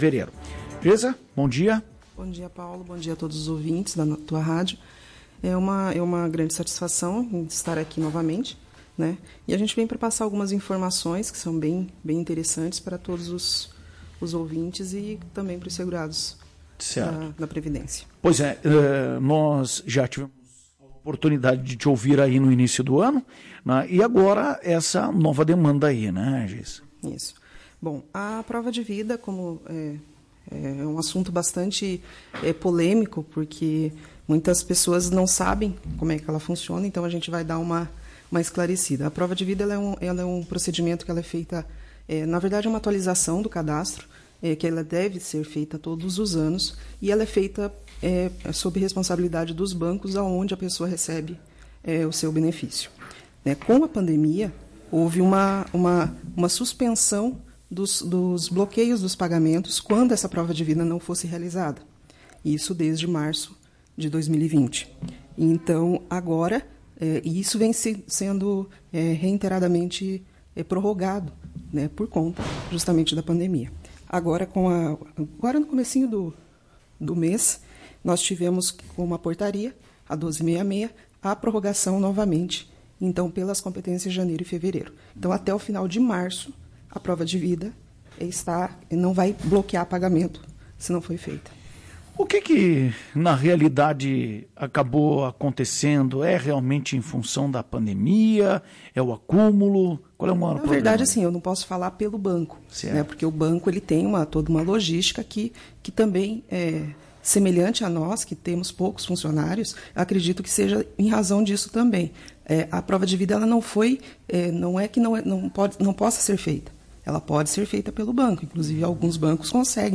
Em entrevista para a Tua Rádio Cacique